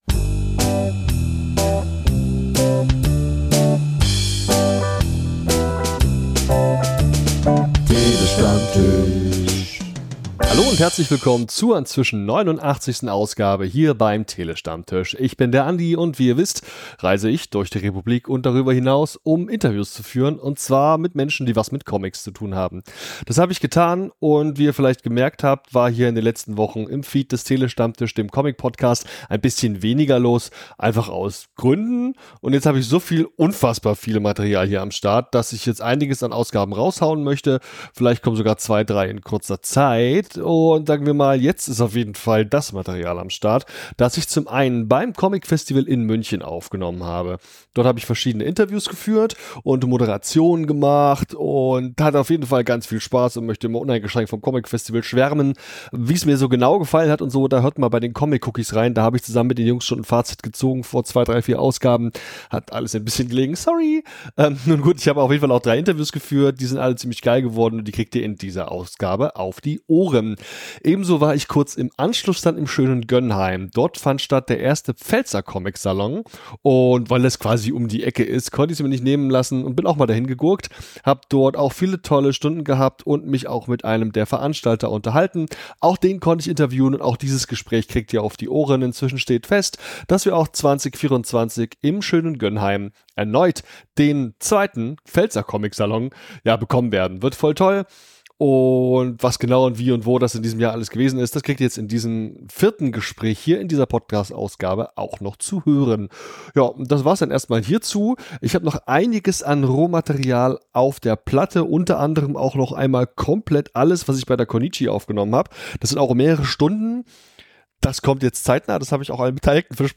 TST089 - Interviews beim Comicfestival München & 1. Pfälzer Comicsalon 2023 ~ Der Tele-Stammtisch